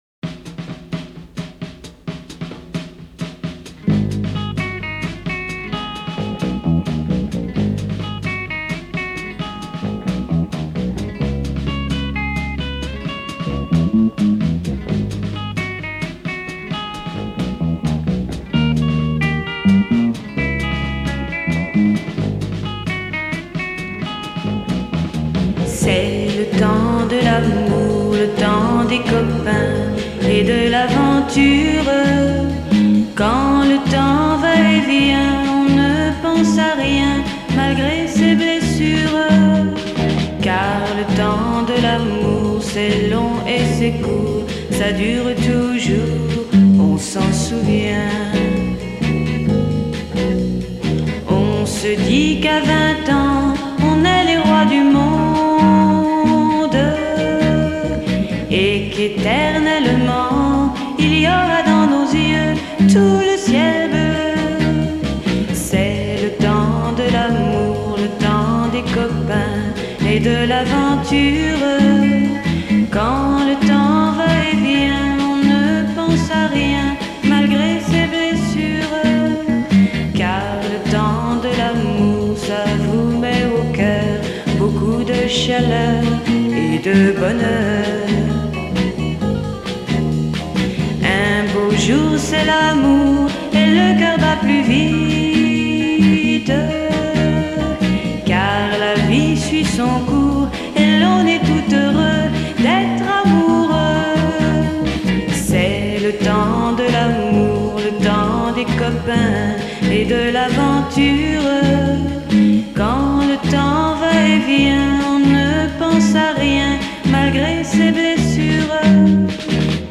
Very melancholic…